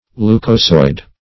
Meaning of leucosoid. leucosoid synonyms, pronunciation, spelling and more from Free Dictionary.
Search Result for " leucosoid" : The Collaborative International Dictionary of English v.0.48: Leucosoid \Leu"co*soid\ (-soid), a. [NL.